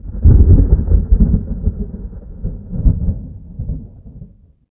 thunder32.ogg